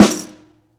Jingle Snare OS 04.wav